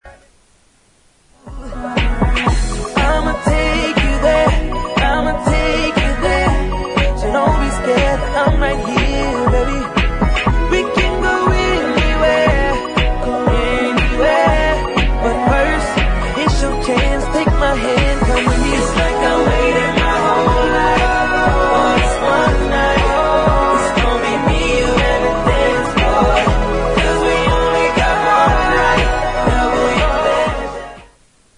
• R&B Ringtones